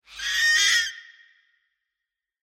Screech Fox 2 Sound Effect Free Download
Screech Fox 2